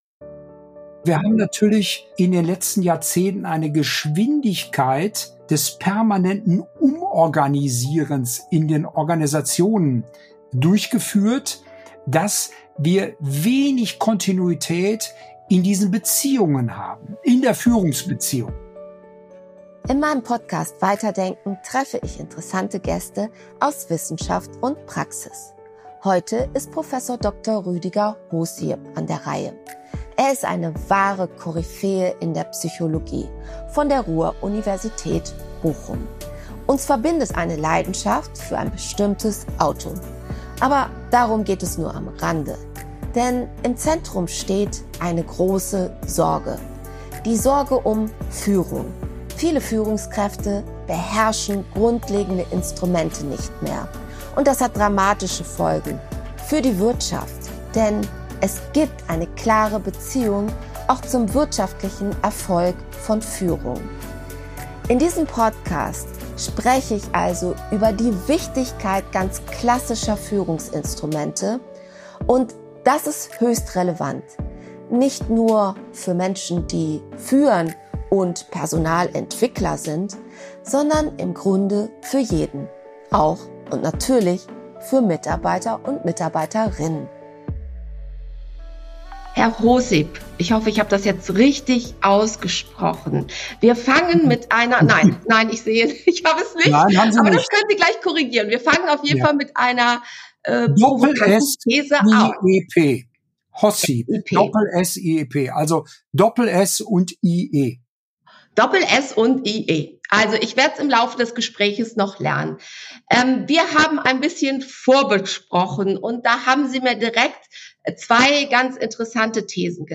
Weiterdenken